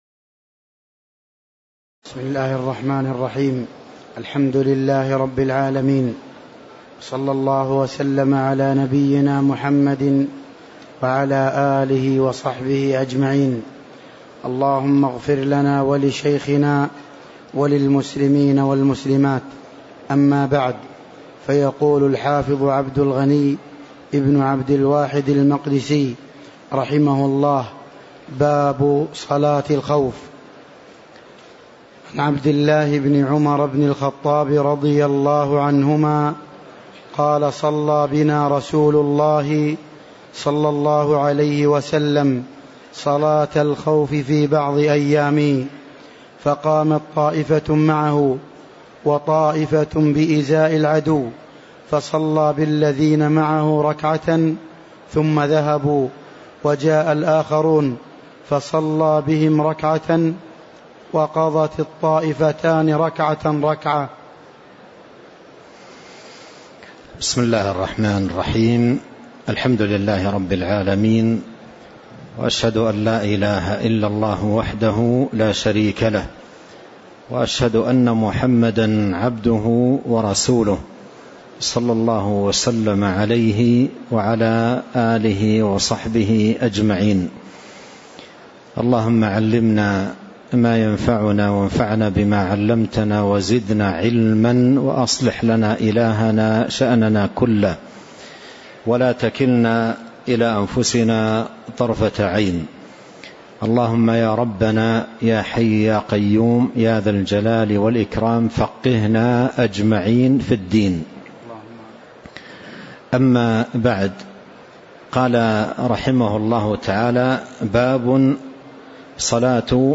تاريخ النشر ٢٥ جمادى الأولى ١٤٤٤ هـ المكان: المسجد النبوي الشيخ